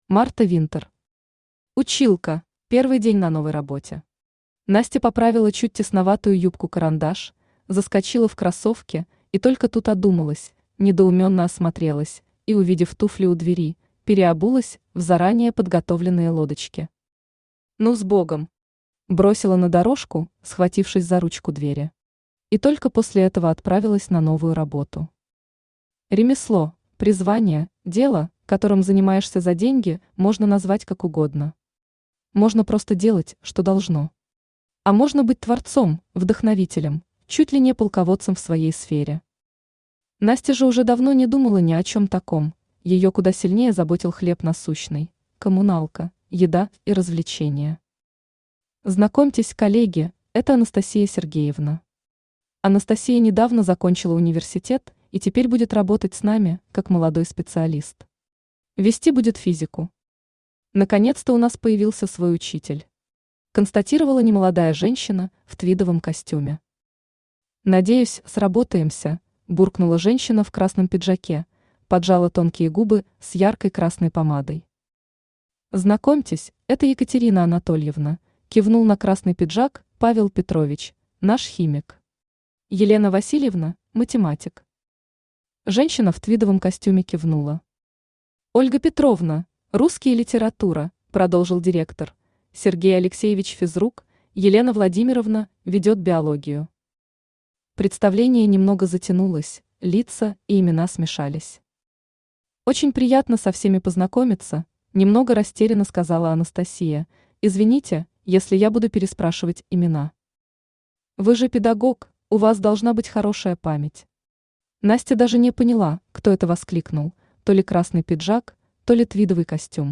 Аудиокнига Училка | Библиотека аудиокниг
Aудиокнига Училка Автор Марта Винтер Читает аудиокнигу Авточтец ЛитРес.